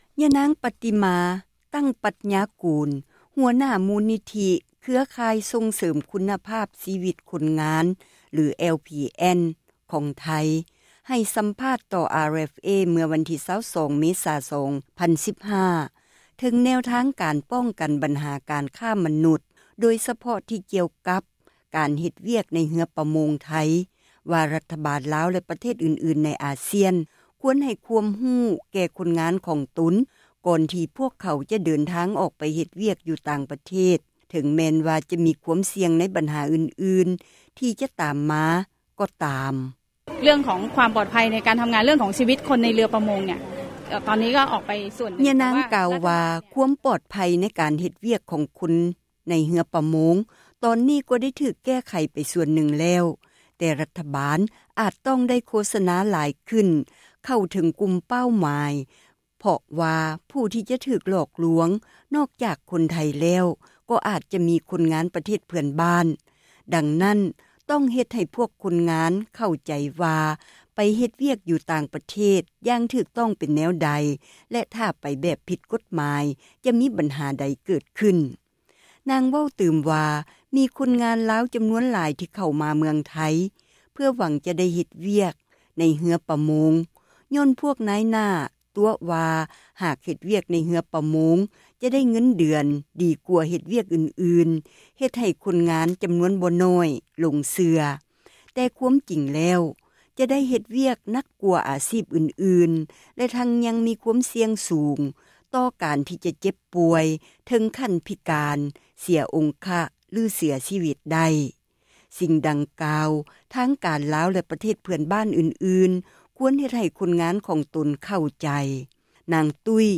ໃຫ້ ສັມພາ ດຕໍ່ RFA